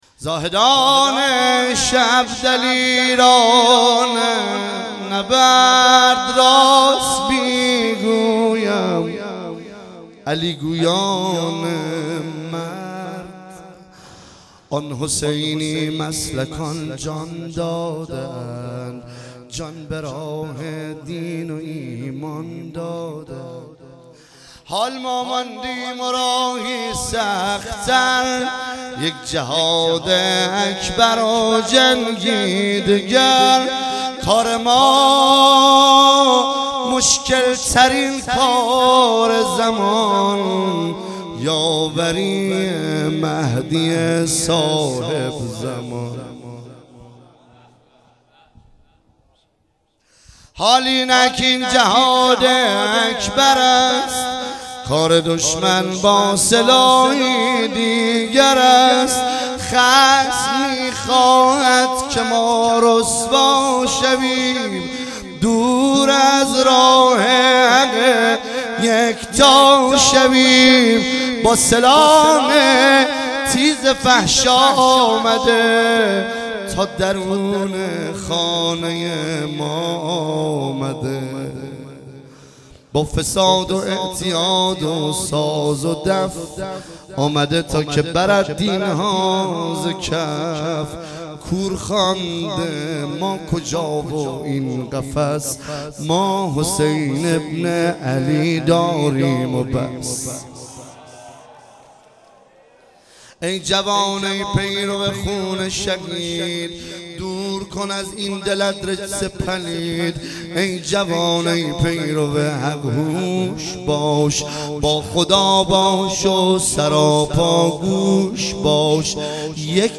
مراسم یادبود ۱۶۷ شهید فرماندهی انتظامی خراسان
شب ۱۶ محرم الحرام ۱۳۹۶
شعرخوانی